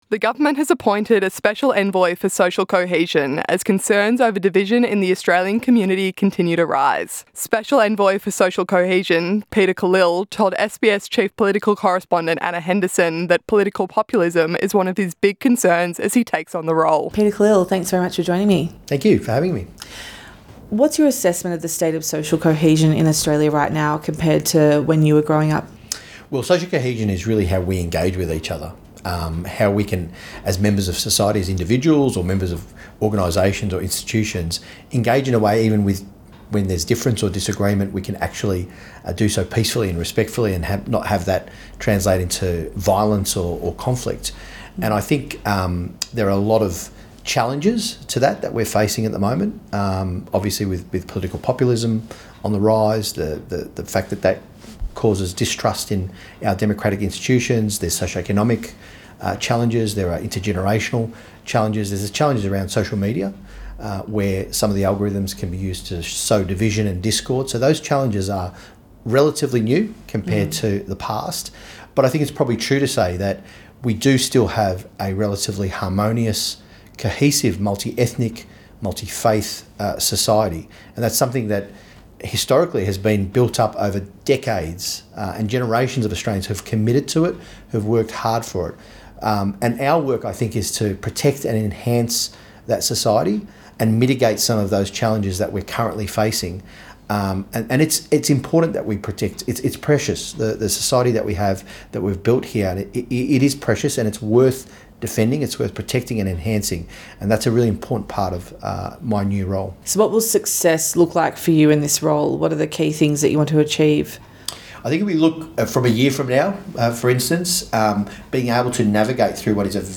INTERVIEW: SBS speaks to Special Envoy for Social Cohesion Peter Khalil